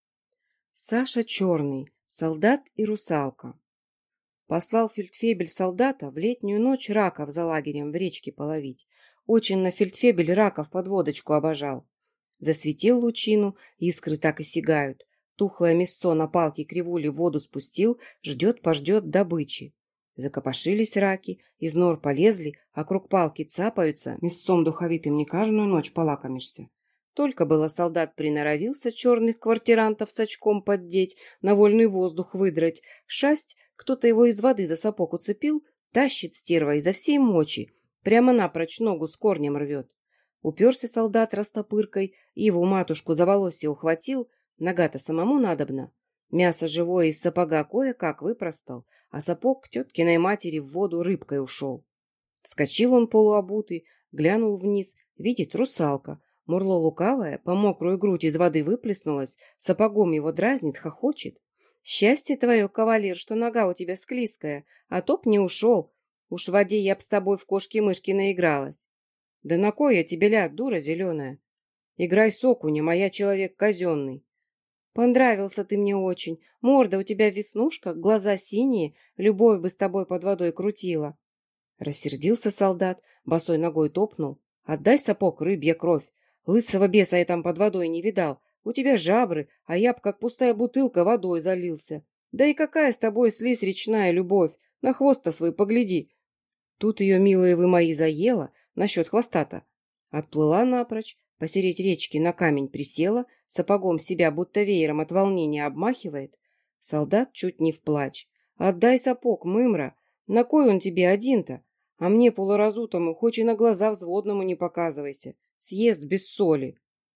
Аудиокнига Солдат и русалка | Библиотека аудиокниг